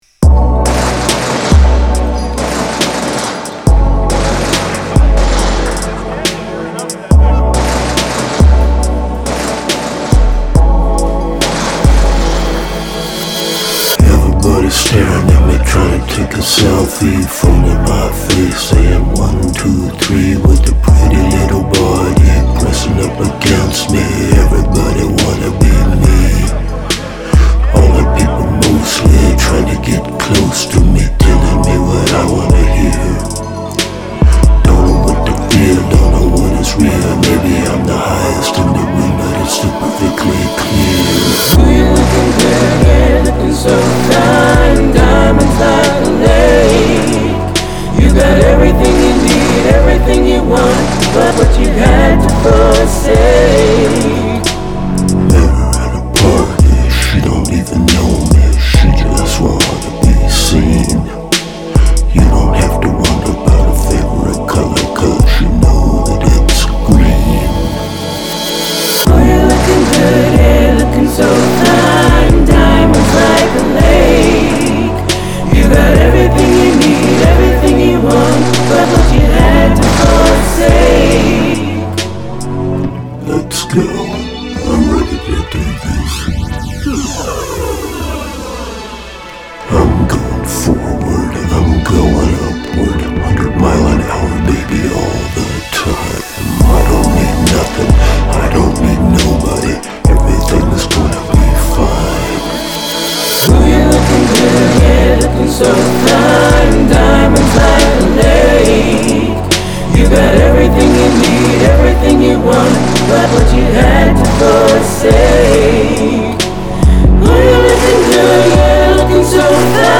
Diamonds - rap-esque tune